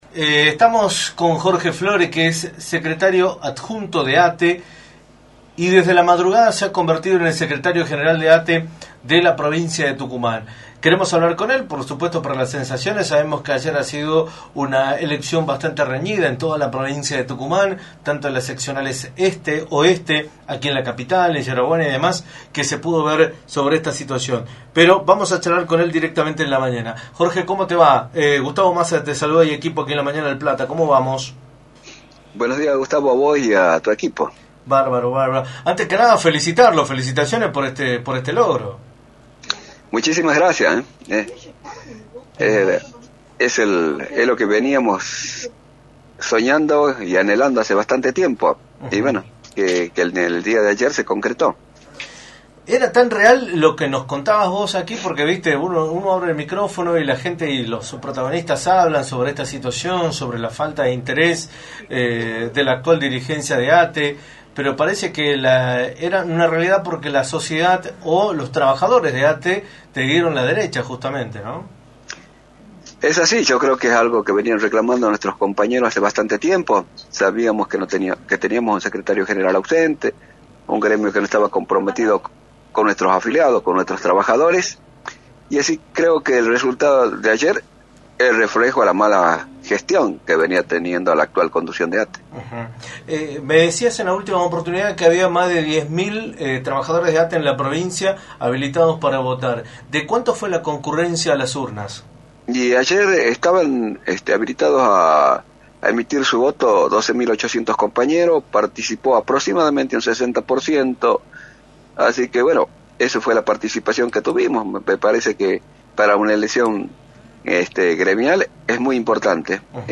entrevista para “La Mañana del Plata”, por la 93.9.